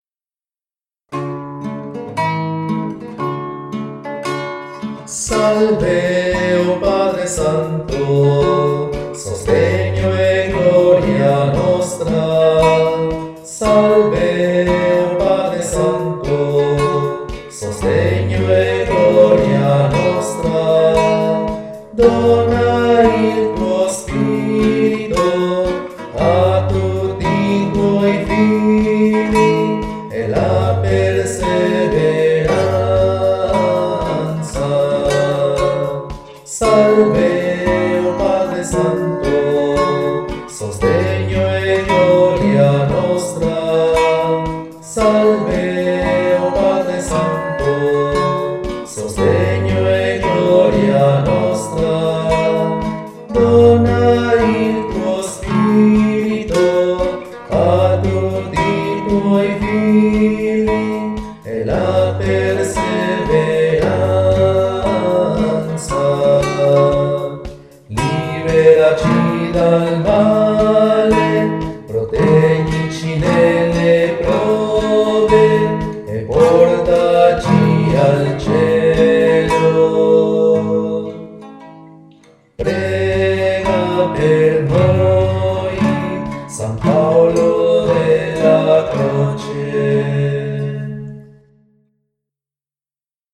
Inno a S. Paolo della Croce, sul testo del Salve Sancte Pater